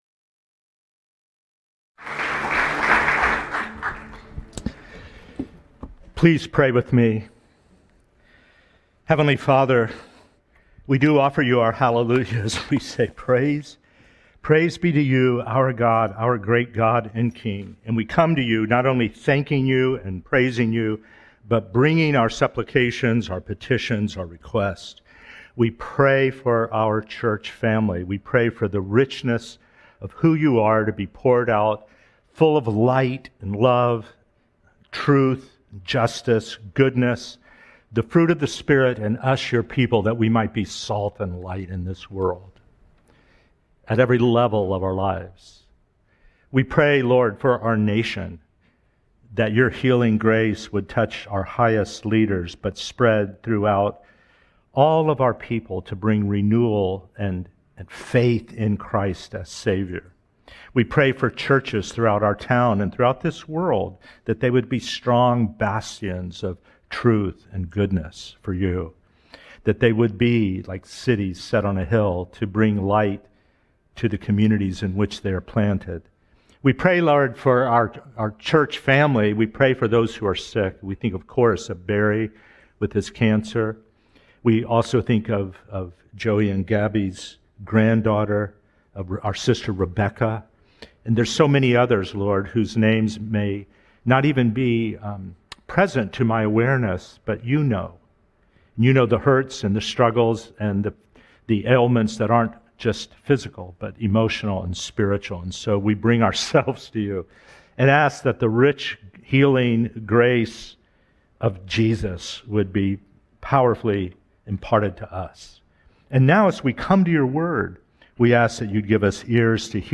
Sermons Christ Community Church: Daytona Beach, FL